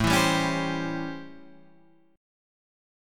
A7#9 chord